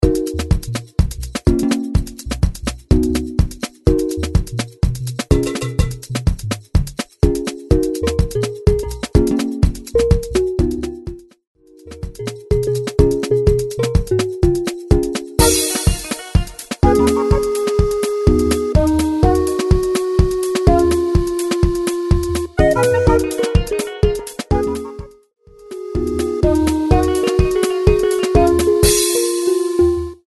125 BPM
Soft Gqom
Gqom